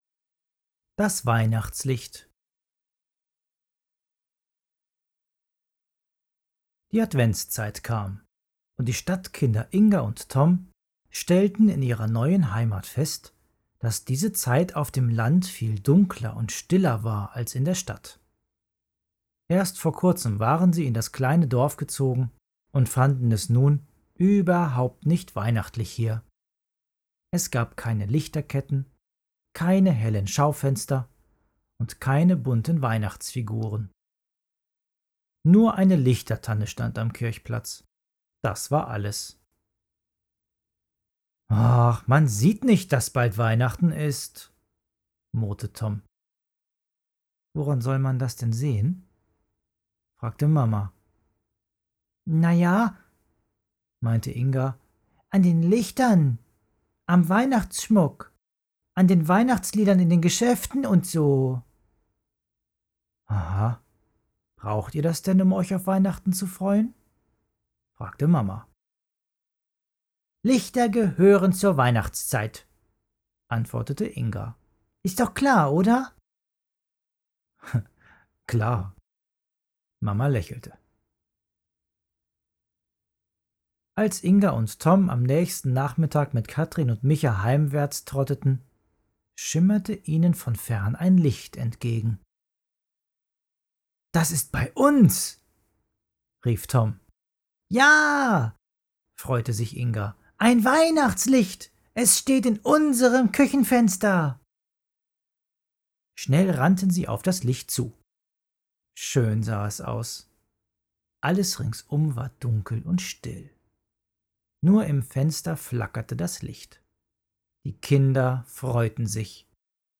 Adventsgeschichte für Kinder